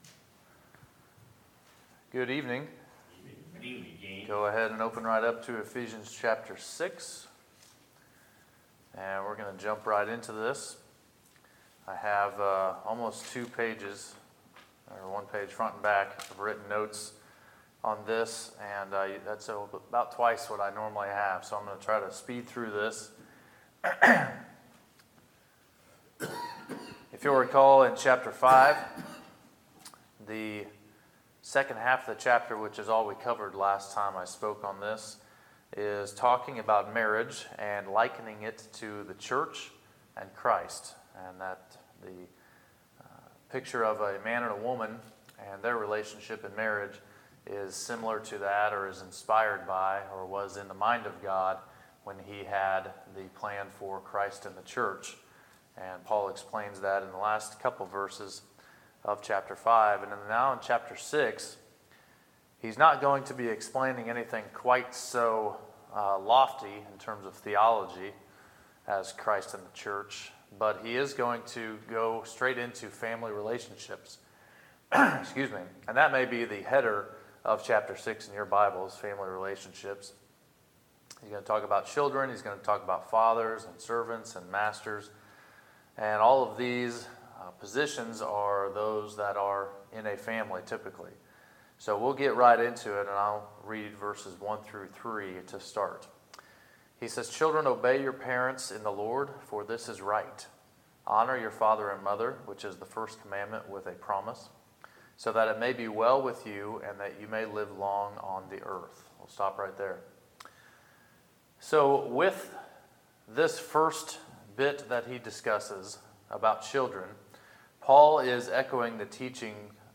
Sermons, May 20, 2018